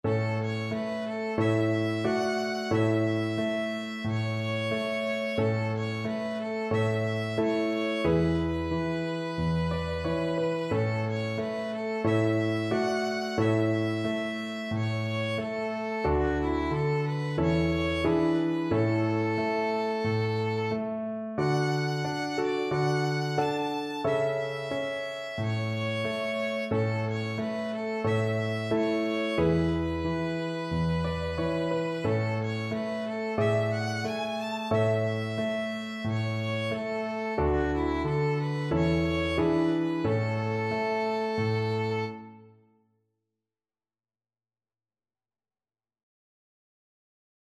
Violin
A major (Sounding Pitch) (View more A major Music for Violin )
Andante =c.90
4/4 (View more 4/4 Music)
Traditional (View more Traditional Violin Music)